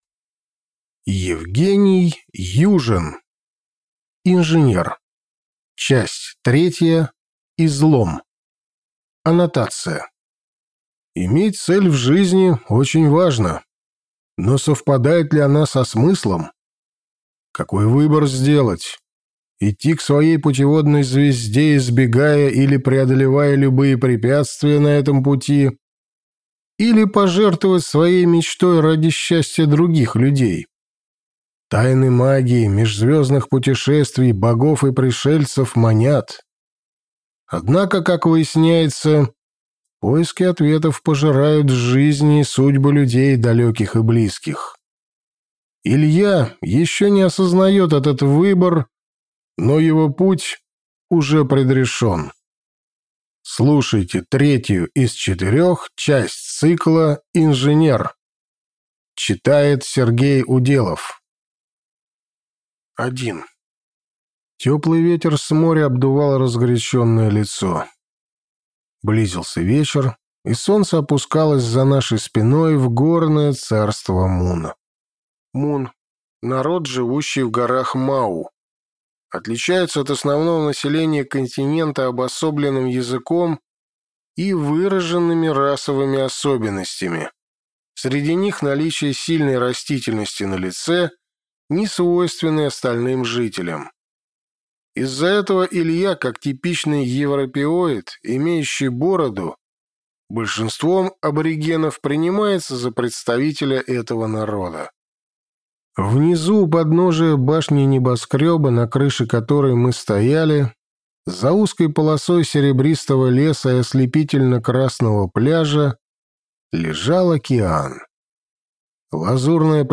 Аудиокнига Инженер. Часть 3. Излом | Библиотека аудиокниг